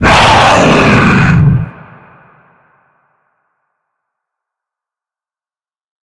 die_0.ogg